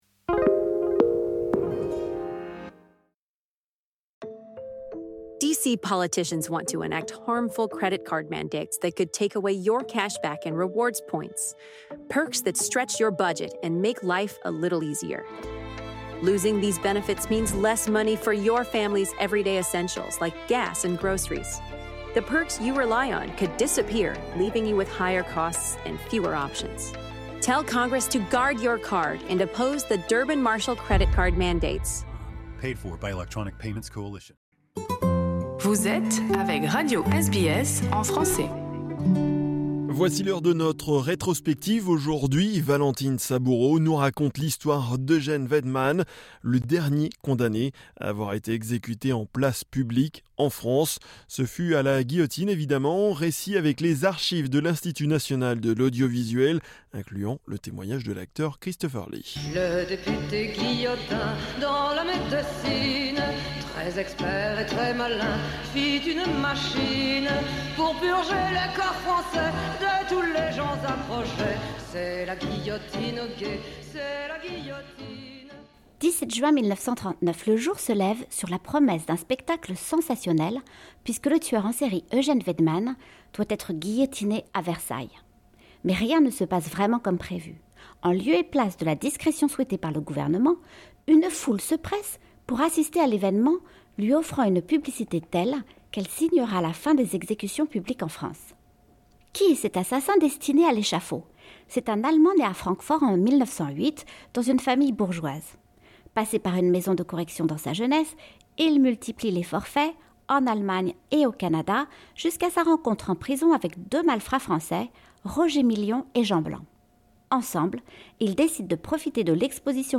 Ce fut à la guillotine évidemment. Récit avec les archives de l’Institut National de l’Audiovisuel incluant le témoignage de l'acteur Christopher Lee.